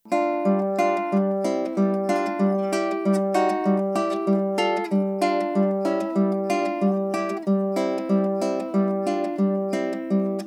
classical guitar - Making Music - Audacity Forum
The 1st is with now effects
the 2nd minimal noise reduction---->normalize---->limiter [default settings ] —>amplify----> minimal reverb
It’s mono.
I like that test6 is a bit brighter (more treble) than untitled.wav. “untitled.wav” sounds a bit dull to me.